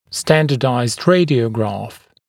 [‘stændədaɪzd ‘reɪdɪəugrɑːf][‘стэндэдай’зд ‘рэйдиоугра:ф]стандартизированная рентгенограмма, обычная рентгенограмма